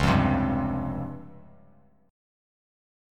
C#M7b5 chord